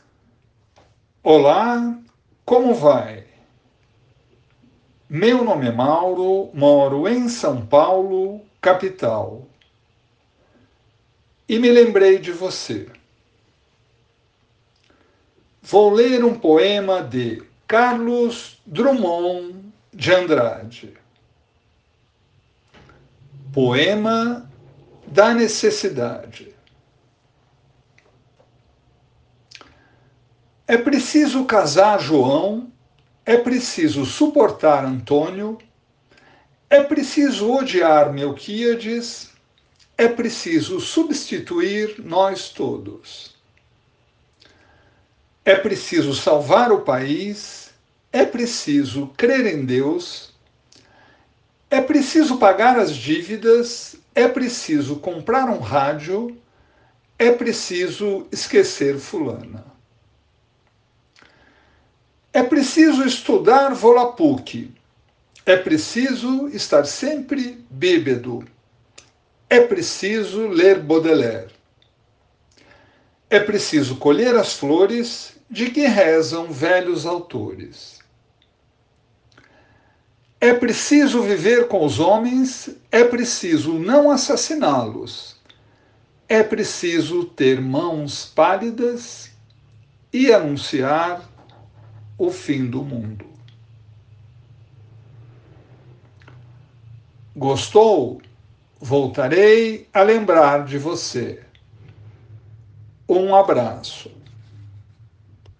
Poema Português